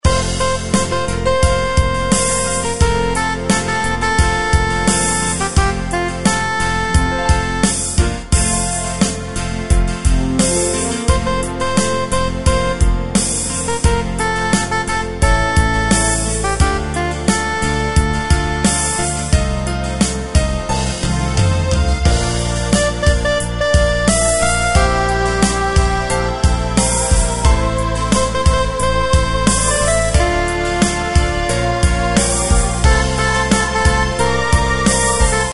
Tempo: 78 BPM.
MP3 with melody DEMO 30s (0.5 MB)zdarma